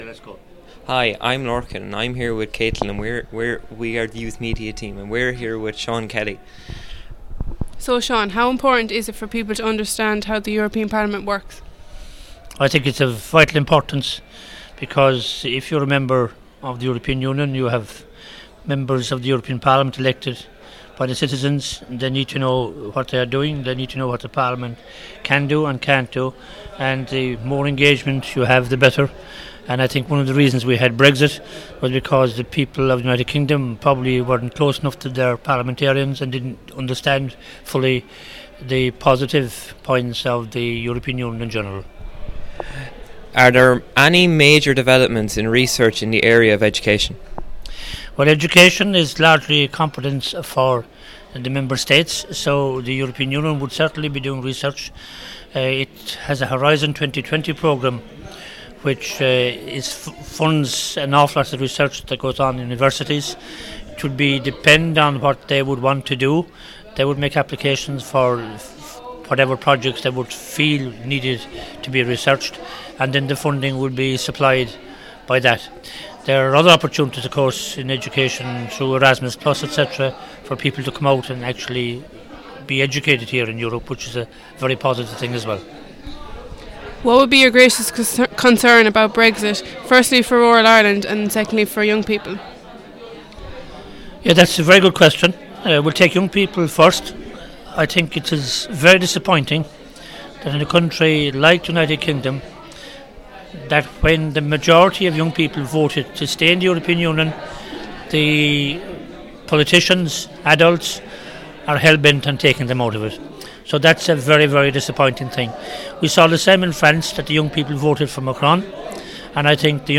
Interview with Sean Kelly MEP